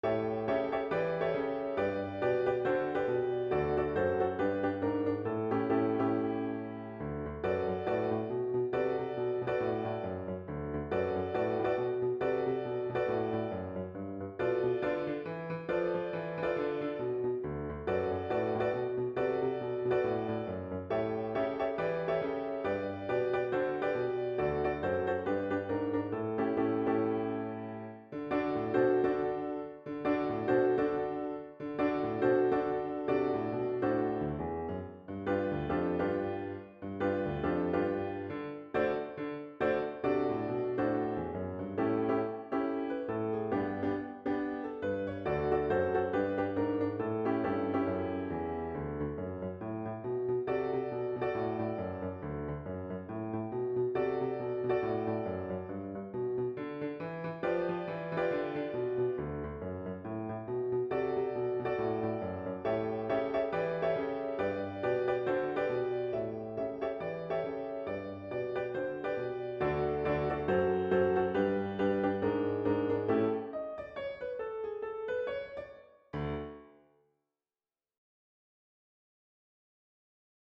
DOWNLOAD PIANO MP3